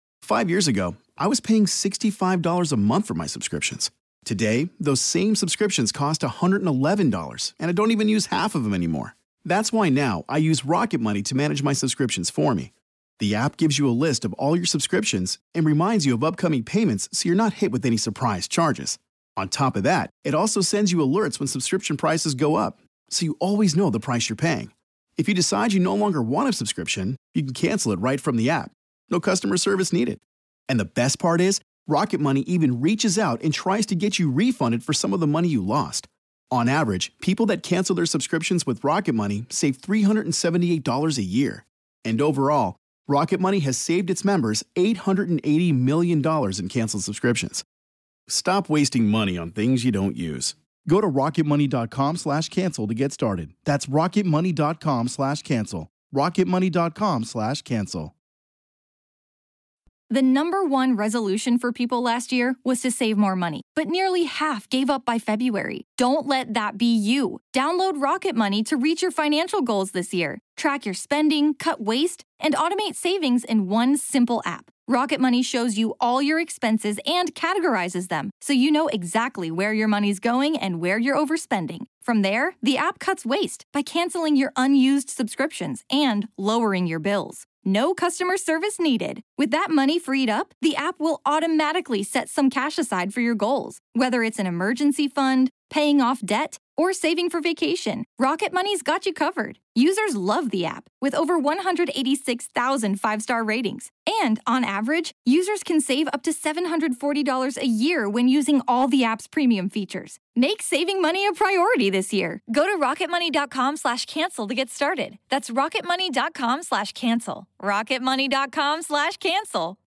Introduction voice over
Narrated